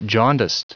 Prononciation du mot jaundiced en anglais (fichier audio)
Prononciation du mot : jaundiced